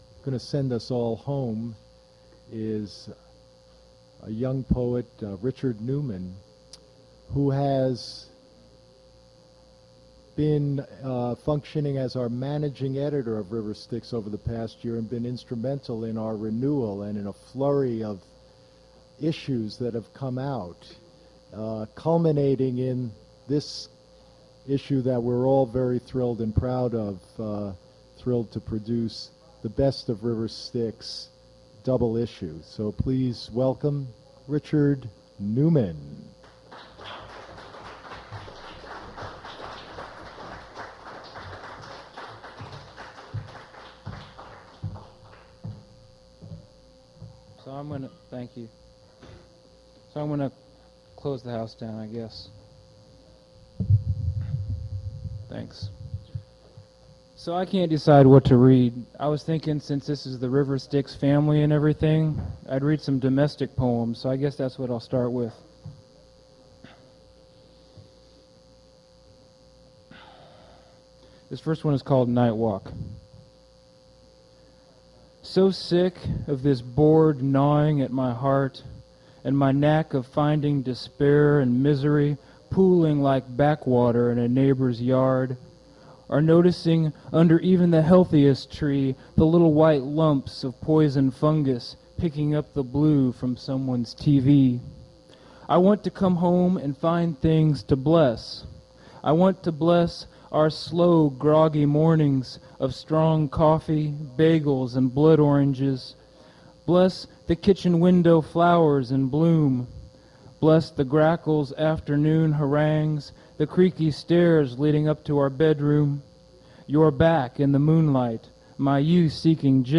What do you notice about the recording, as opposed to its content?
• recodings starts with introduction